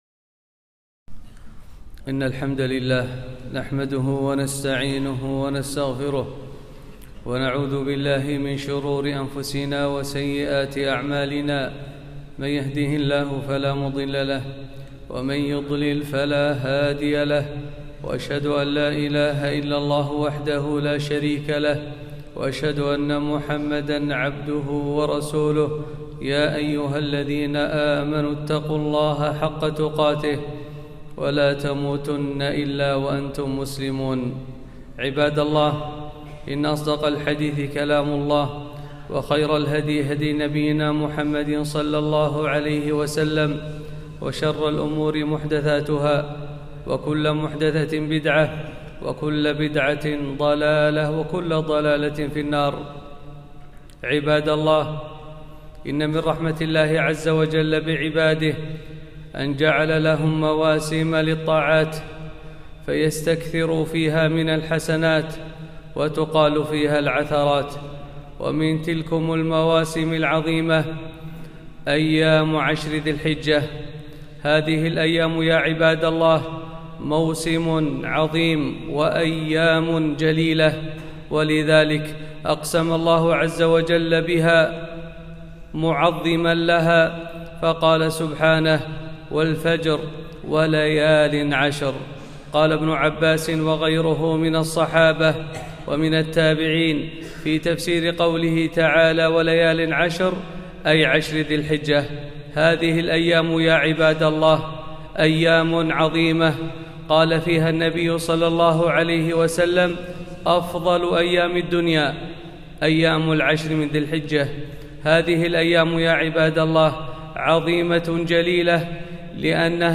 خطبة - أفضل أيام الدنيا